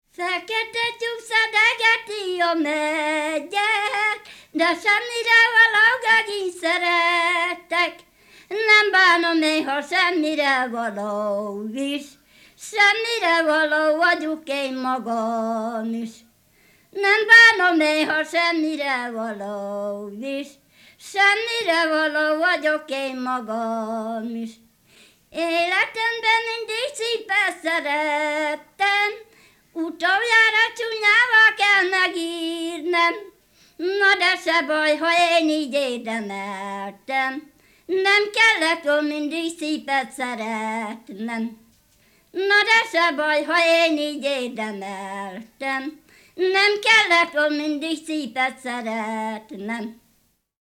Multipart Music, Instrumentation of Sound, Instrumentalization of Sound, Sound and Society, Performance as Instrumentation, Tradition, Revival
Folk & traditional music